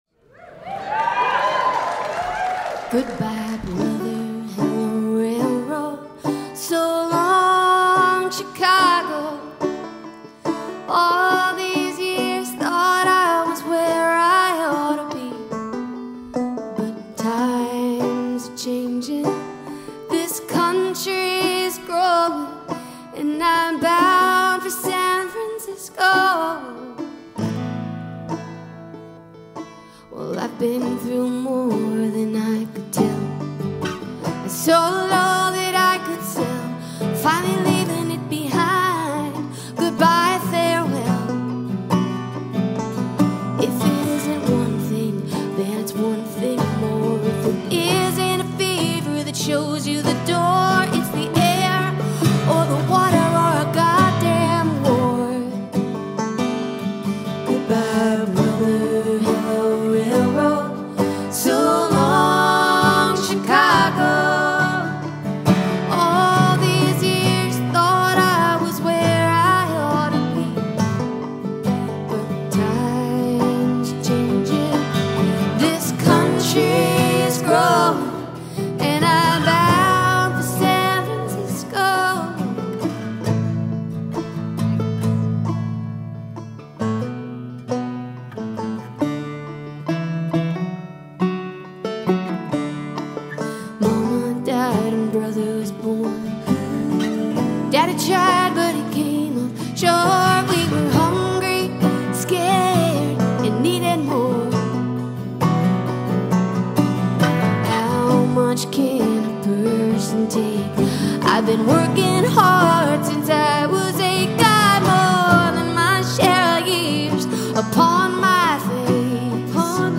It keeps a steady rhythm that feels just right.